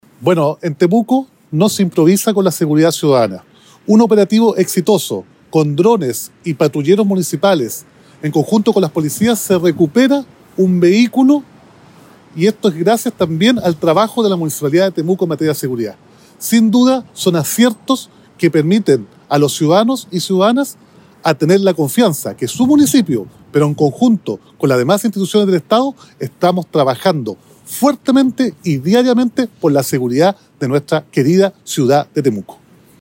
Roberto-Neira-alcalde-de-Temuco-1.mp3